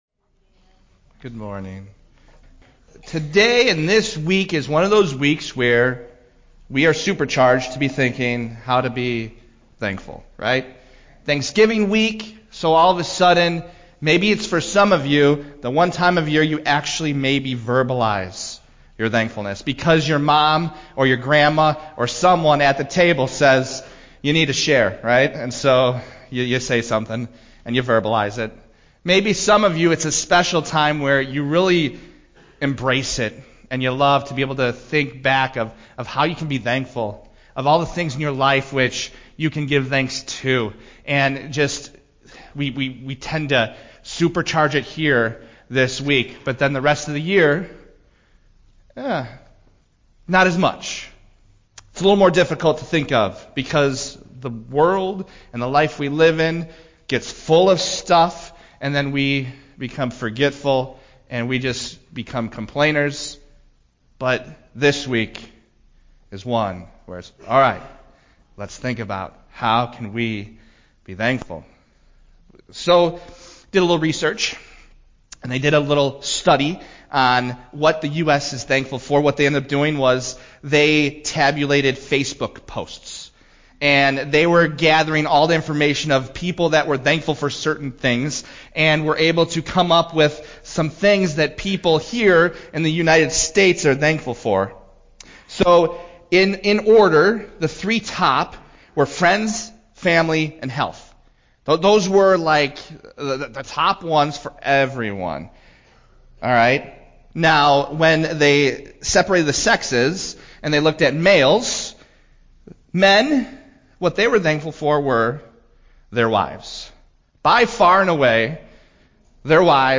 Thanksgiving Week message